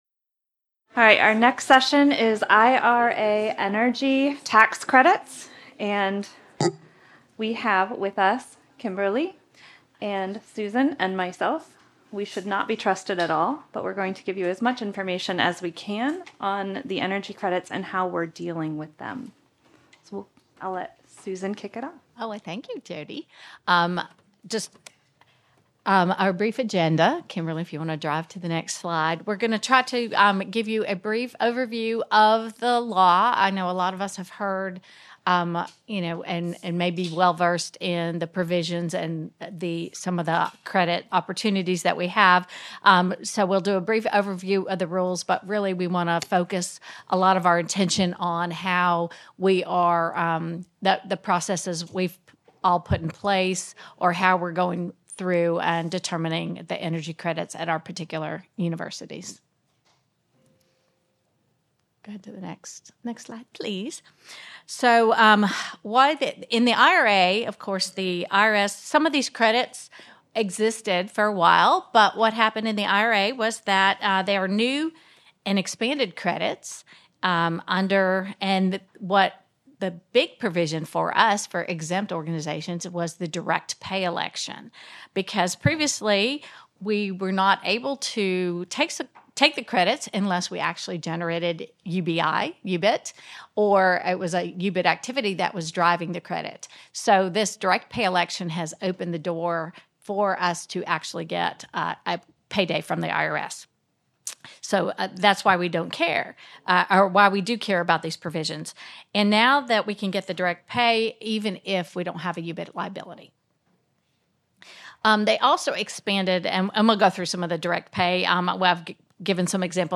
Originally presented: Jun 2024 Higher Education Taxation Institute